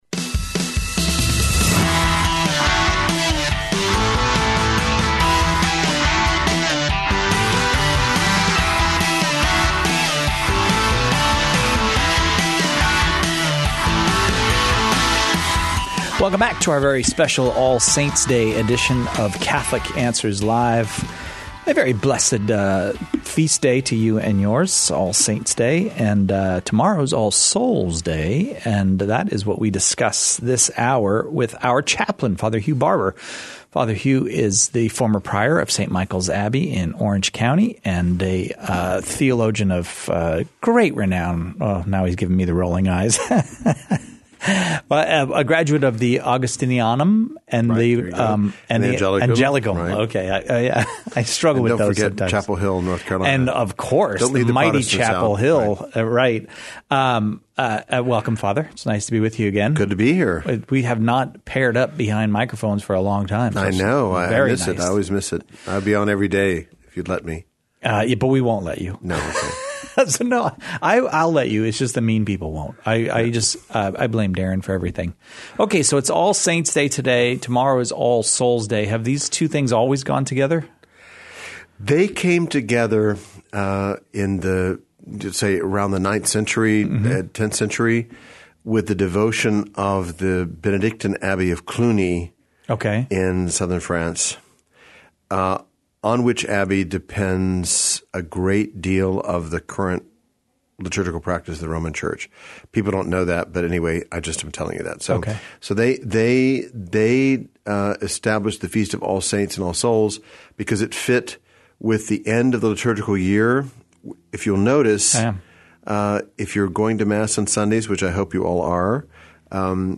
(Pre-record)